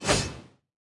Media:BattleHealer_base_atk_1.wav 攻击音效 atk 初级和经典及以上形态攻击音效